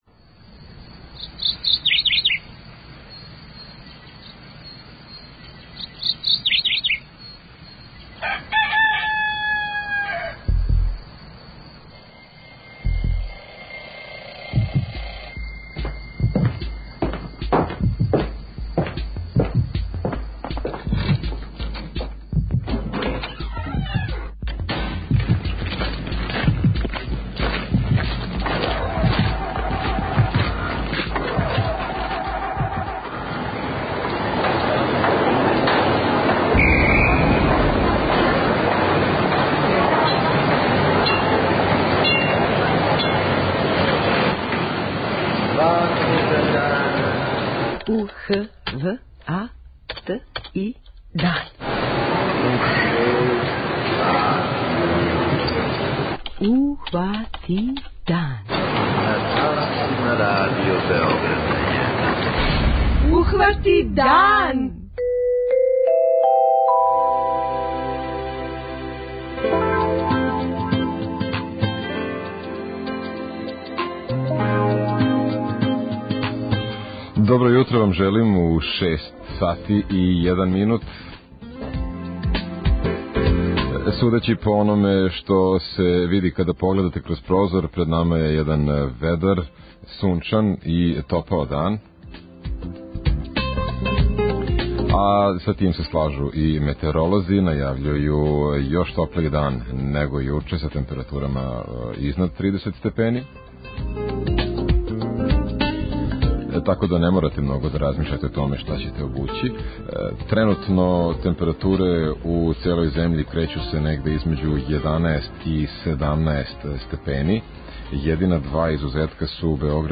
преузми : 21.57 MB Ухвати дан Autor: Група аутора Јутарњи програм Радио Београда 1!